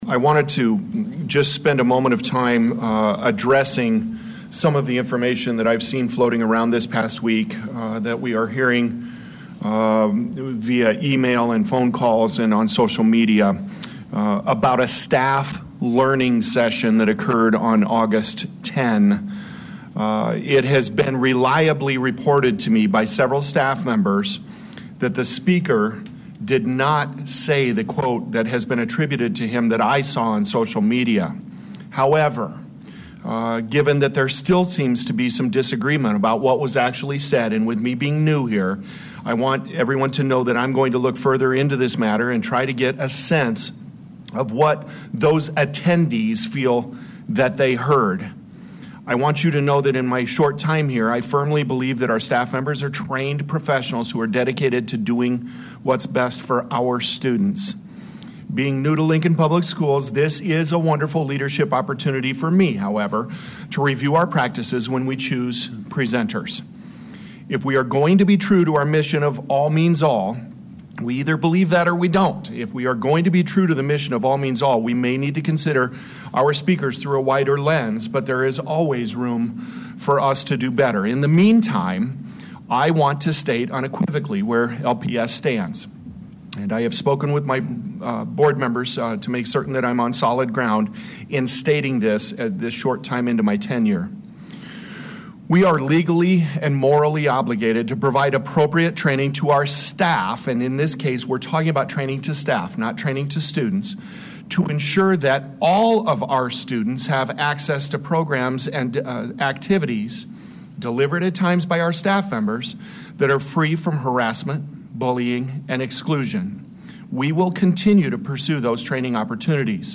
New Lincoln Public Schools Superintendent Dr. Paul Gausman began Tuesday’s school board meeting with a statement aimed at recent controversy over a staff training event held earlier this month.